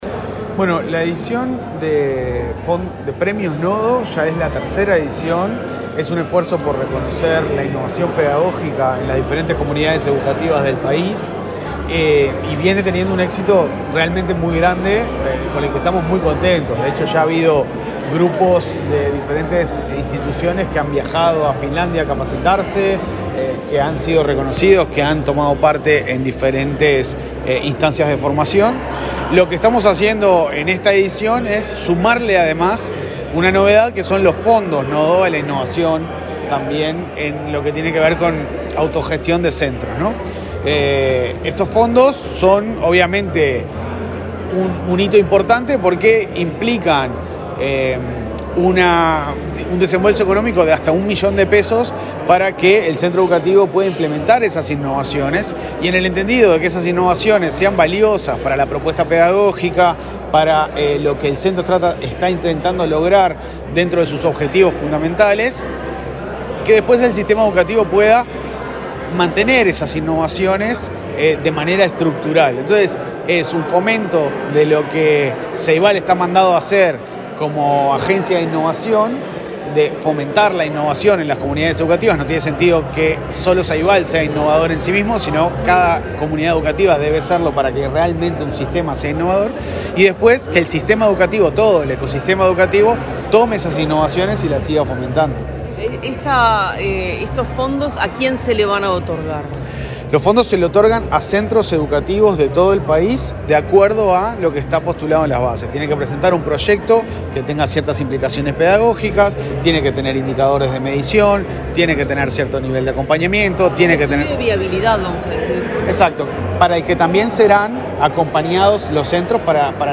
Declaraciones del presidente del Ceibal, Leandro Folgar
Declaraciones del presidente del Ceibal, Leandro Folgar 02/08/2023 Compartir Facebook X Copiar enlace WhatsApp LinkedIn El presidente de Ceibal, Leandro Folgar, dialogó con la prensa, antes de participar en el lanzamiento del Premio Nodo, impulsado por el Ministerio de Educación y Cultura, la Administración Nacional de Educación Pública y Ceibal.